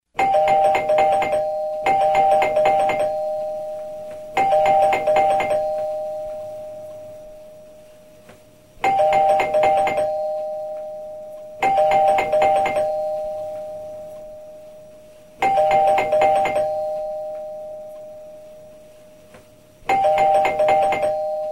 dvernoi-zvonok_24659.mp3